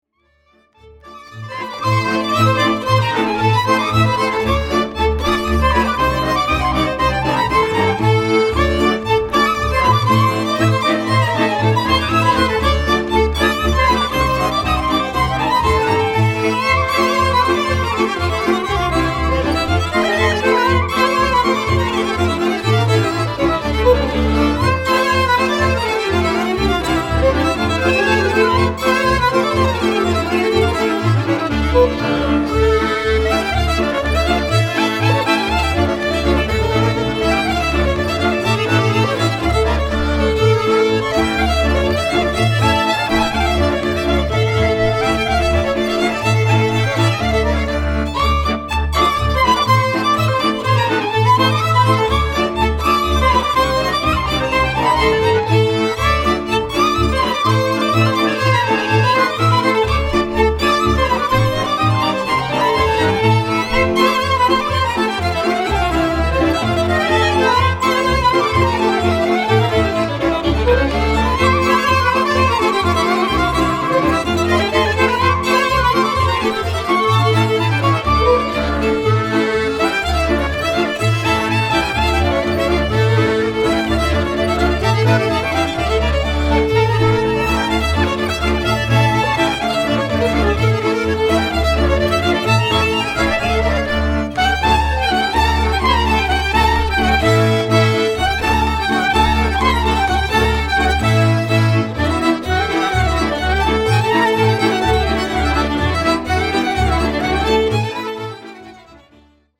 Tsimbl, 19th Century Button Accordion
Three-string Bratsch (Viola), Baraban (Drum)
Klezmer — Instrumental Jewish Music from Eastern Europe.
Genre: Klezmer.